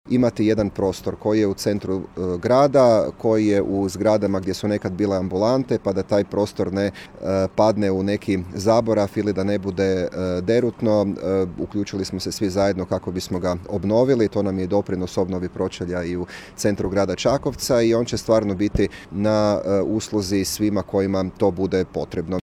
Župan Matija Posavec: